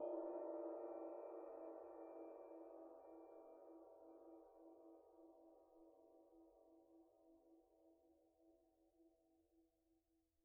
susCymb1-hit_pp_rr1.wav